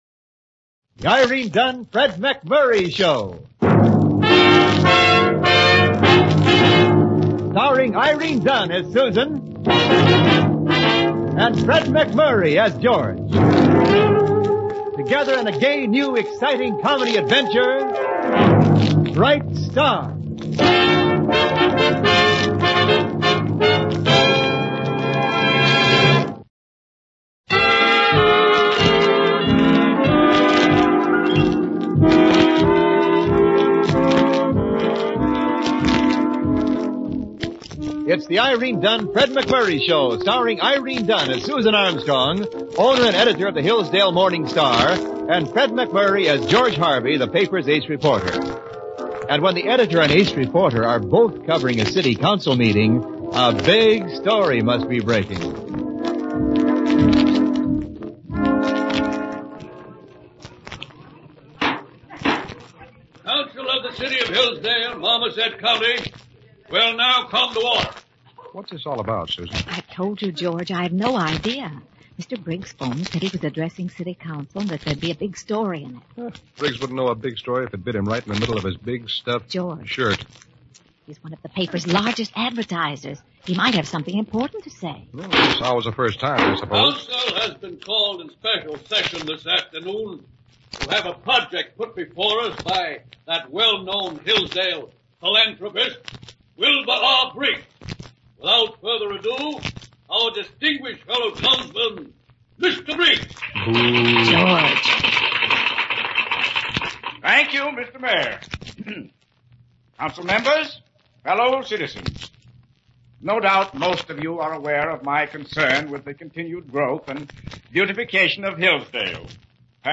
Bright Star, starring Irene Dunne and Fred MacMurray